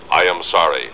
Lost in Space TV Show Sound Bites